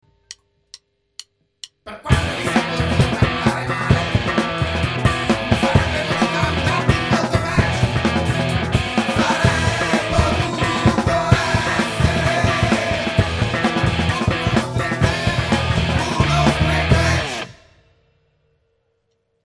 Ruvidi e grezzi, ma sempre con tante cose da dire.